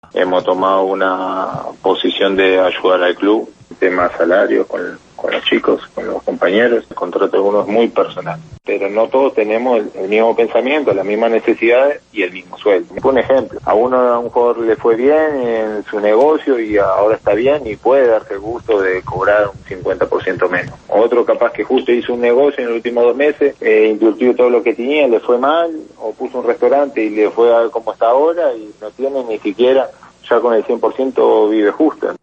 (Sebastián Viera, portero del Junior)